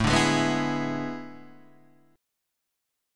A Major 7th Suspended 2nd Suspended 4th